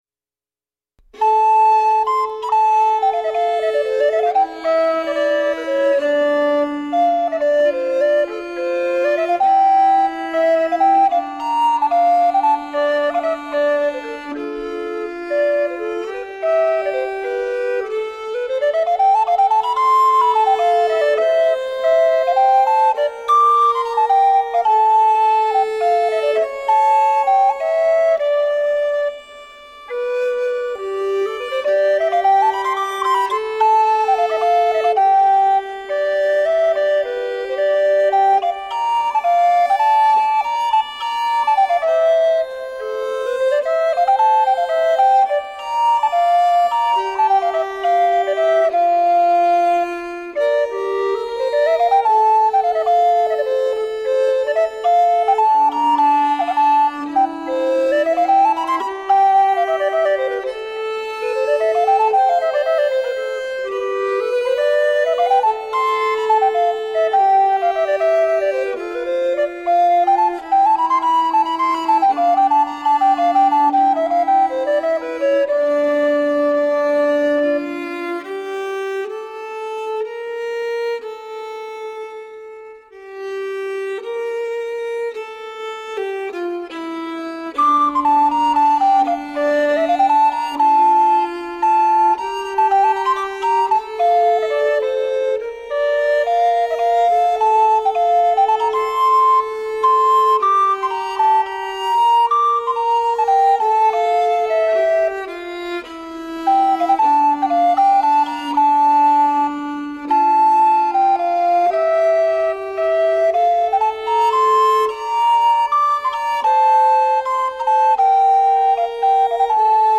Medieval music meets living modal music traditions.
Tagged as: World, Other, Arabic influenced, Recorder